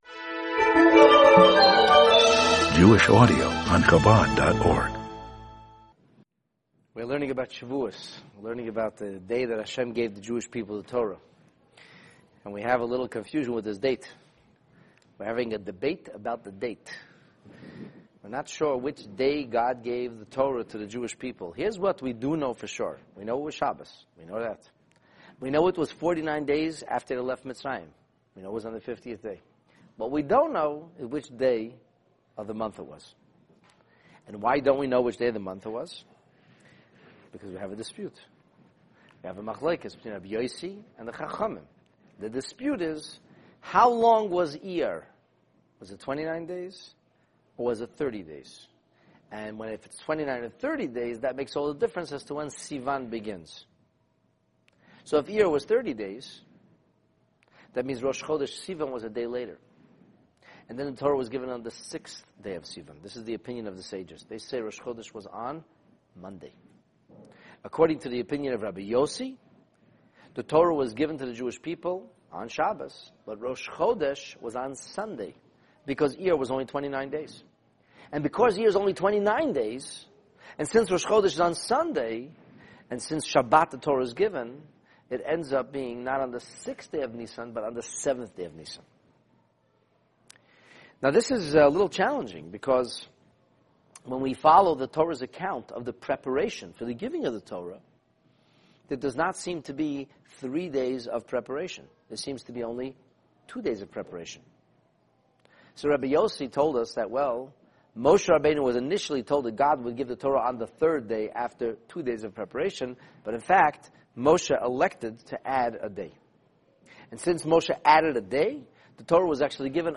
: The Talmud on the date of the giving of the Torah (Part 3) This class analyses the dispute between Rebbi Yose and the Sages regarding the actual date for the Revelation at Sinai. In seeking greater clarity we delve into the Scripture’s detailed description of the necessary preparations that seem to suggest conflicting dates for the actual giving of the Torah. It’s filled with robust cross-examination, analysis, and rebuttal, and that ultimately leads to relative resolution!